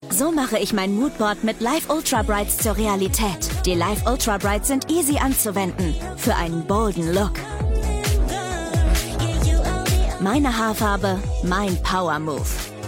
hell, fein, zart, sehr variabel, markant
Jung (18-30)
Commercial (Werbung), Off